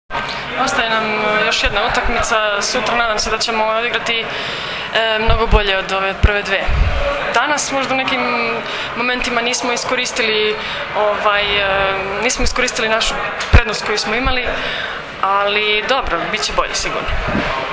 IZJAVA JOVANE VESOVIĆ